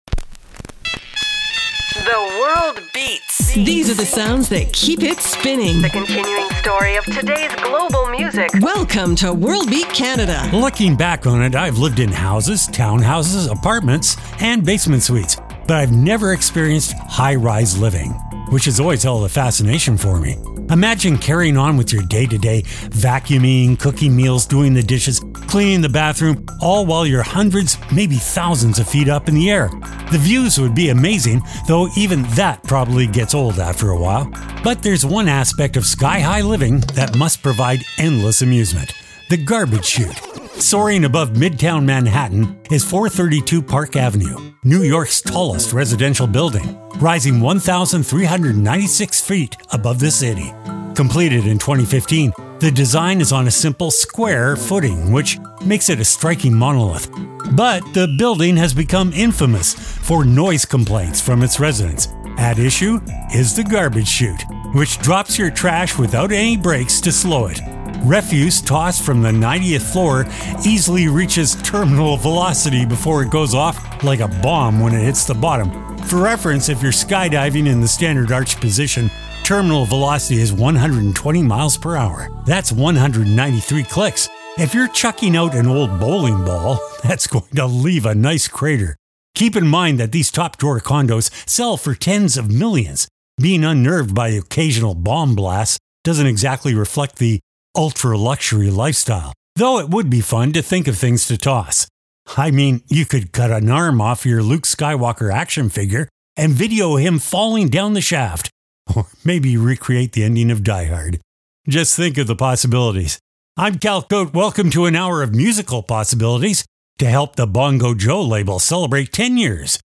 exciting global music alternative to jukebox radio
Weekly Program